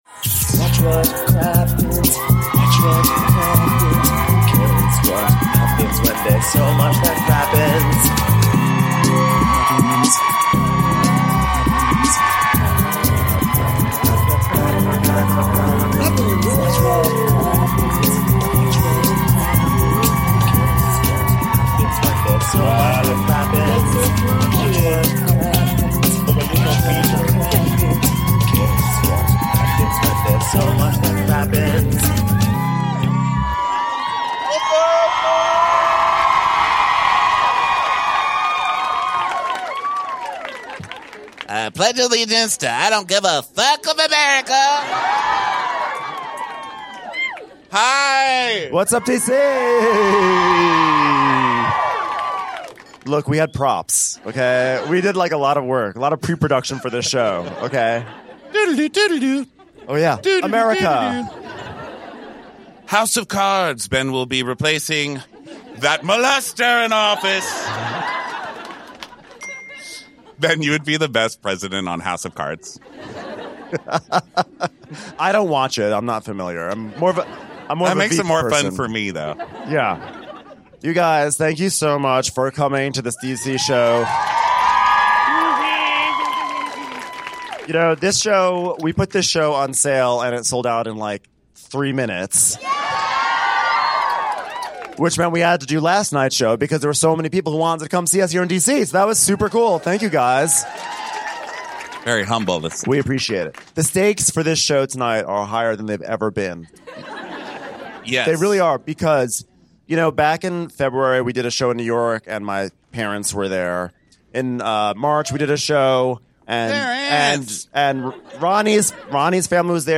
We're live in DC for a second night in a row, and this time we're talking RHOBH.